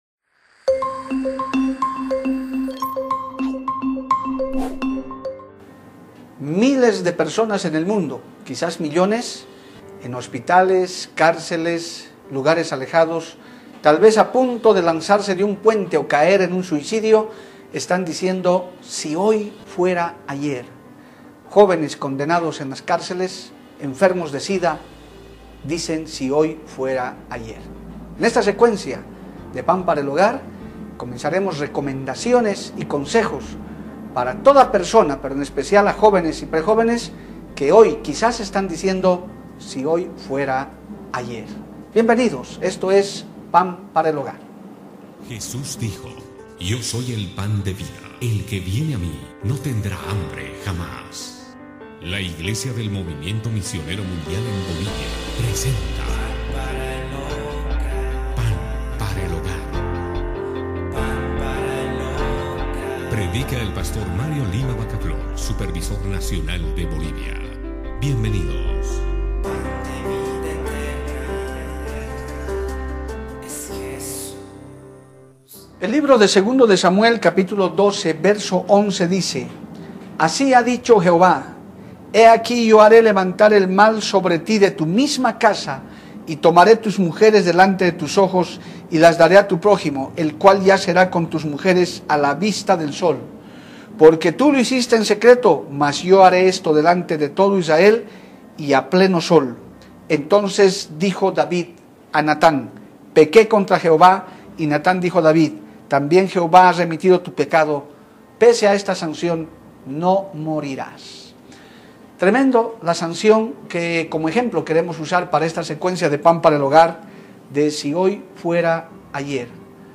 En este primer capítulo de la serie Si hoy fuera ayer, nuestro pastor nos orienta ¿Qué hacer para no caer en este problema de querer retroceder el tiempo?